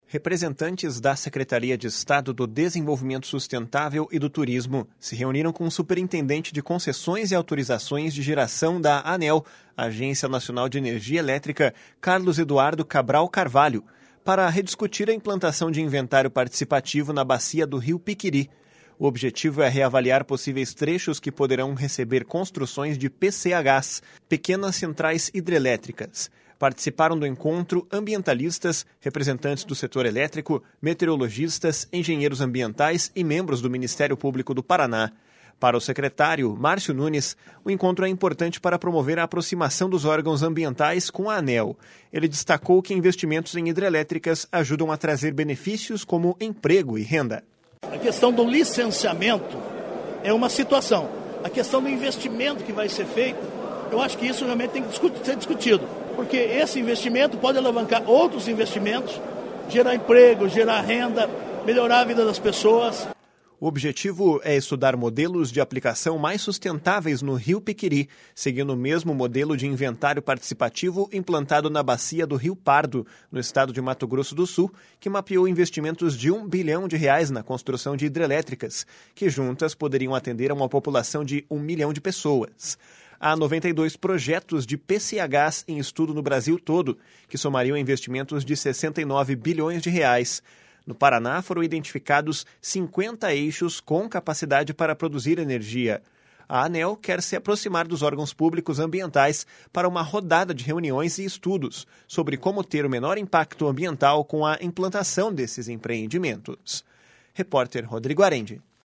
// SONORA MÁRCIO NUNES //